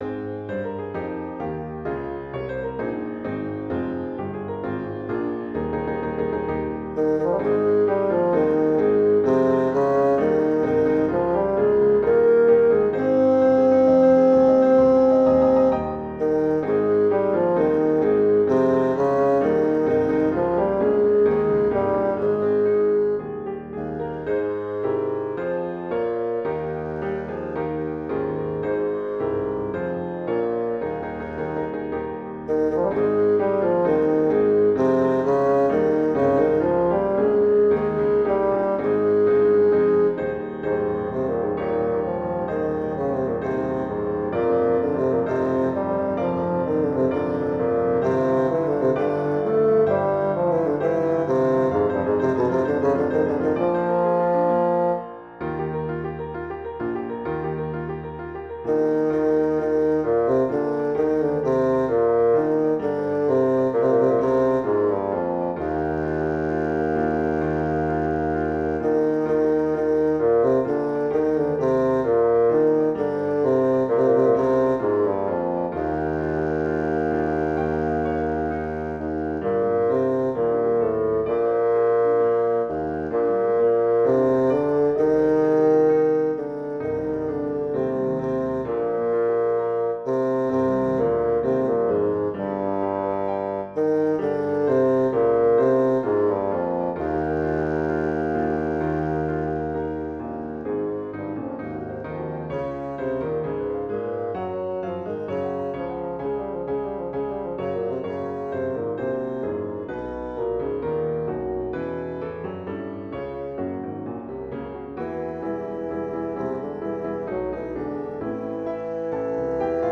Intermediate Instrumental Solo with Piano Accompaniment.